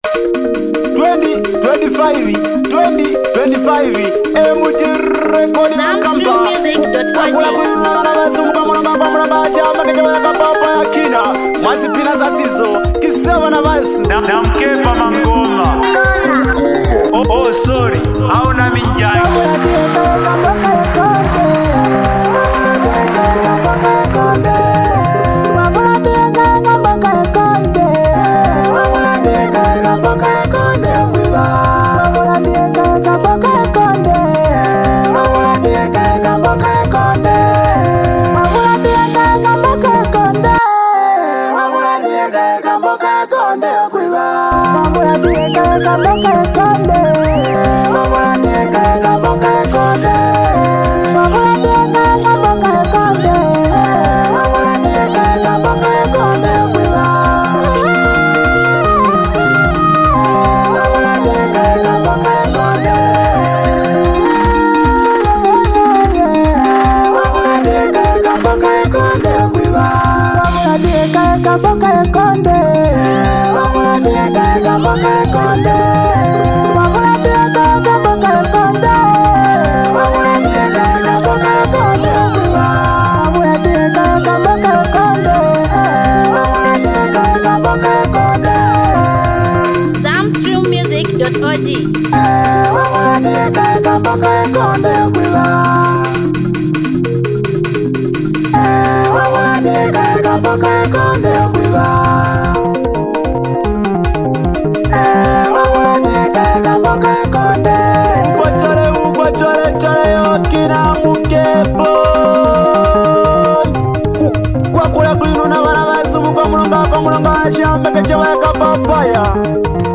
emotional banger
With his raw vocals and honest lyrics
The soulful beat and powerful message